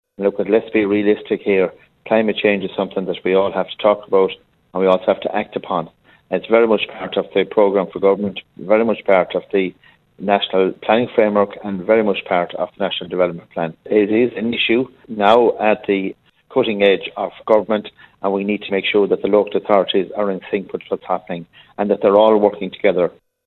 Sean Canney says the Government wants to make Ireland a leader in the field: